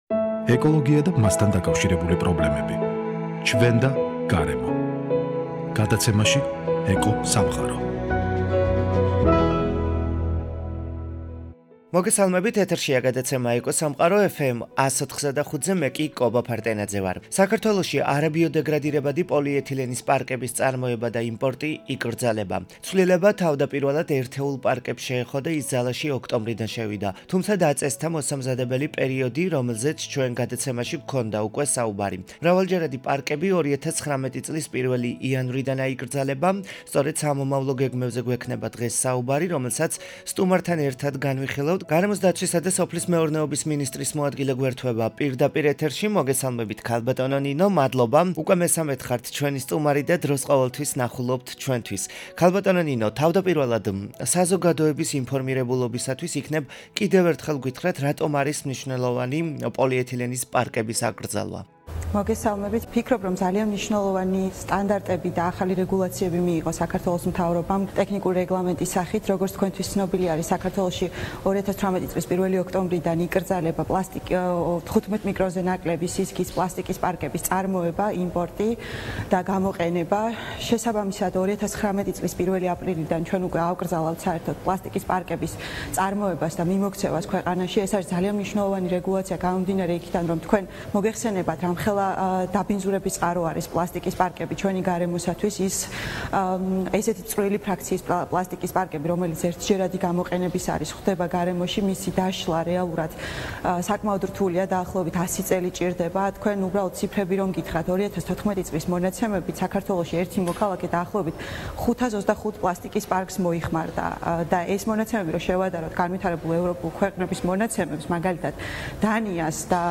საქართველოში არაბიოდეგრადირებადი პოლიეთილენის პარკების წარმოება და იმპორტი იკრძალება! ცვლილება თავდაპირველად ერთეულ პარკებს შეეხო და ის ძალაში ოქტომბრიდან შევიდა, თუმცა დაწესდა მოსამზადებელი პერიოდი. მრავალჯერადი პარკები 2019 წლის 1 იავრიდან აიკრძალება, სწორედ სამომავლო გეგმებზე გვექნება დღეს საუბარი, რომელსაც სტუმართან ერთად განვიხილავთ - გარემოს დაცვისა და სოფლის მეურნეობის მინისტრის მოადგილე ნინო თანდილაშვილი საუბრობს პირდაპირ ეთერში